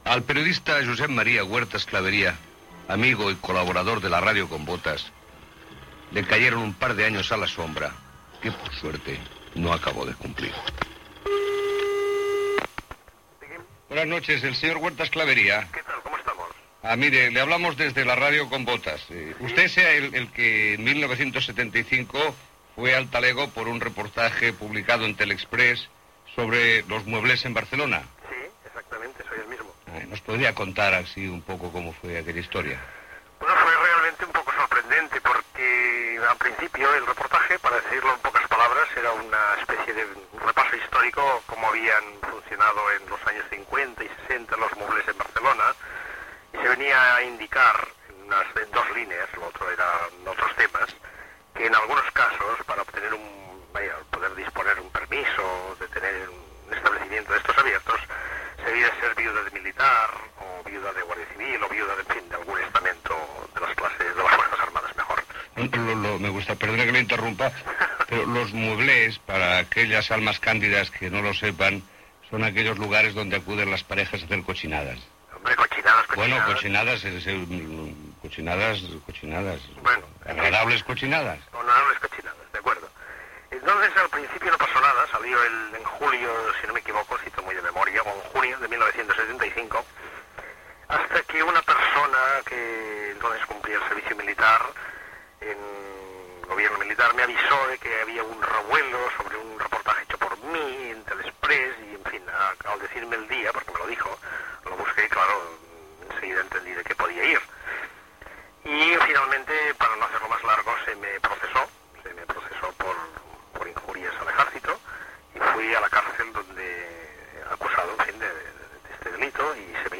Entrevista telefònica
Divulgació